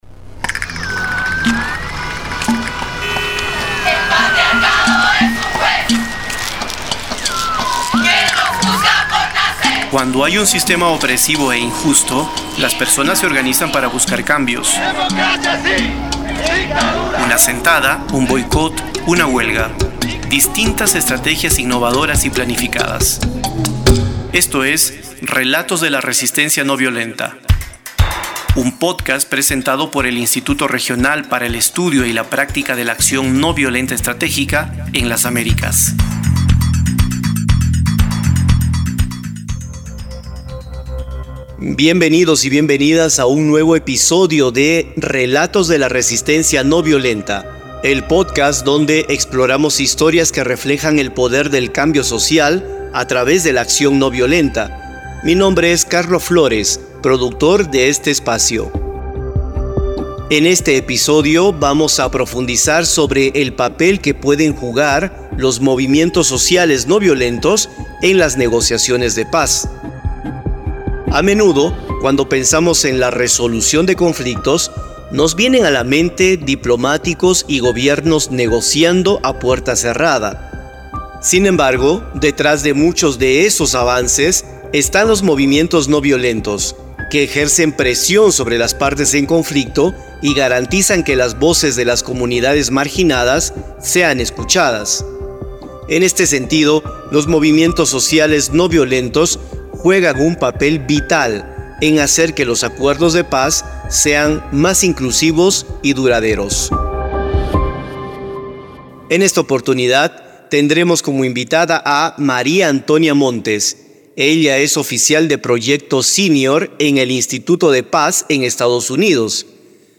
Tamaño: 33.63Mb Formato: Basic Audio Descripción: Entrevista - Acción ...